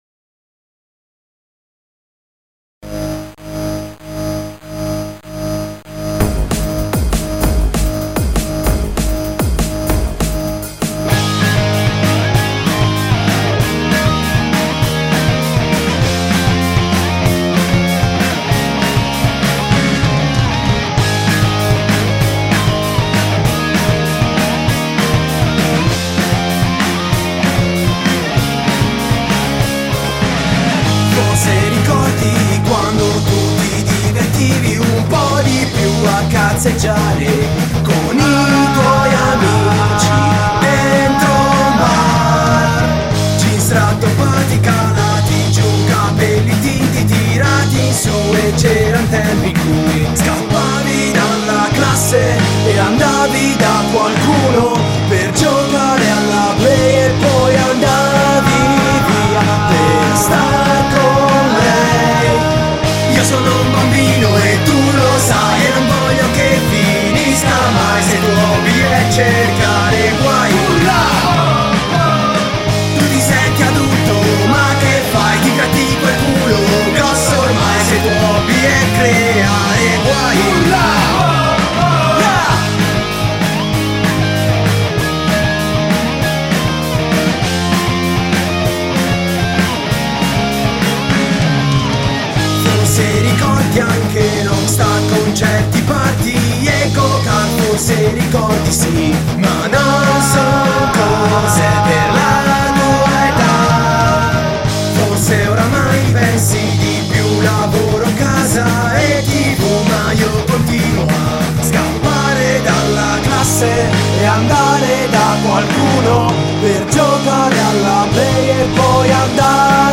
GenereRock / Punk